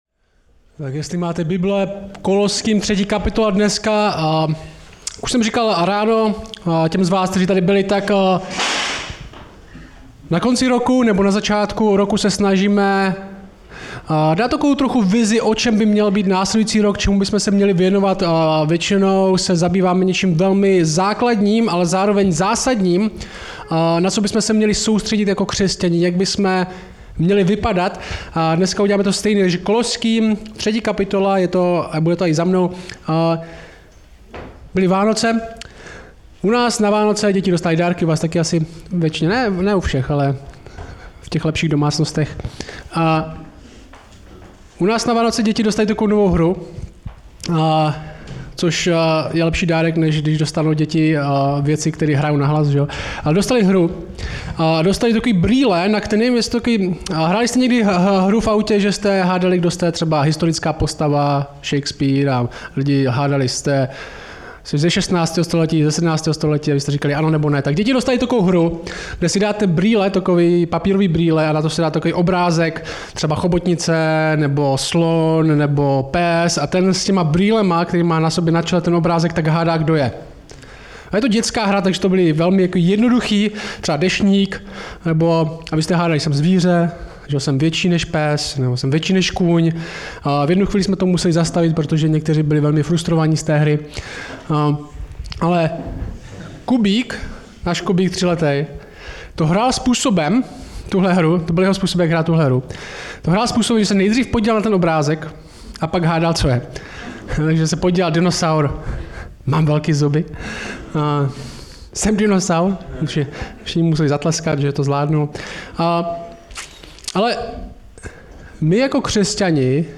Samostatná kázání